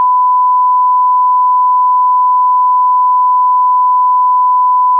tone_8000_mono.wav